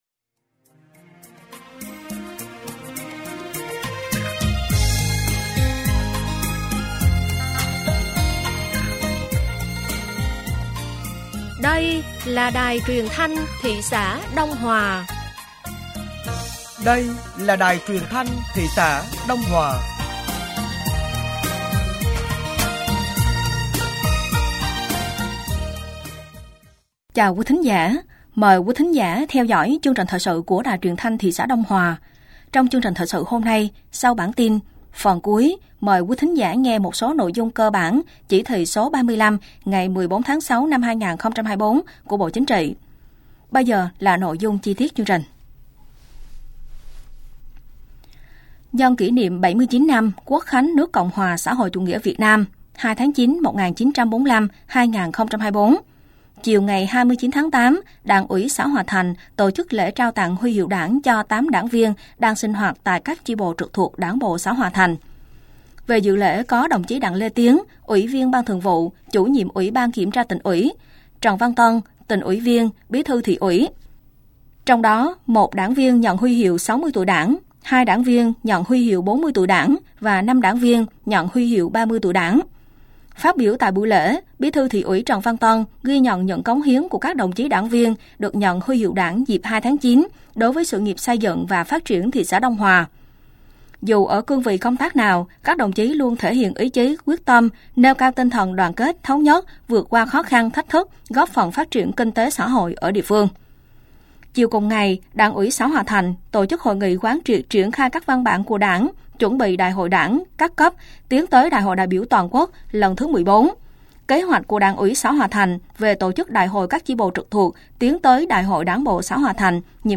Thời sự tối ngày 29 và sáng ngày 30 tháng 8 năm 2024